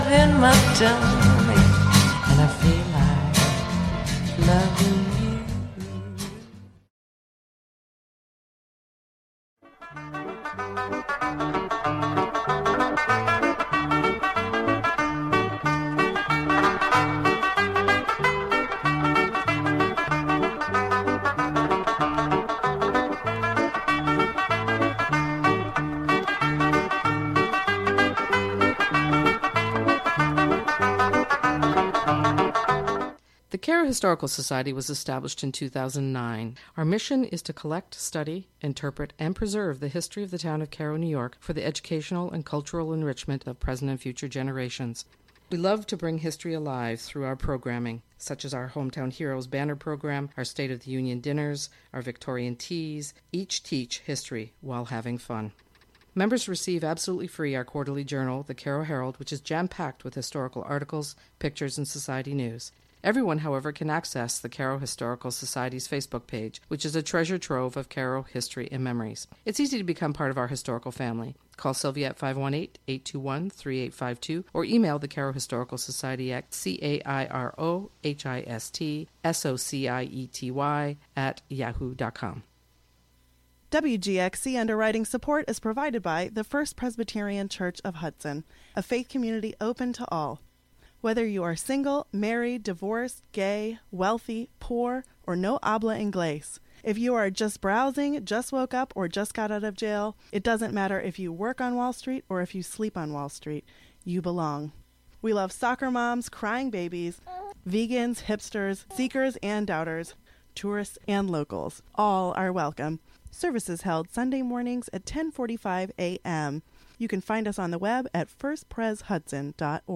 The Irish Show features the best of Irish music, sports results, requests, and interviews.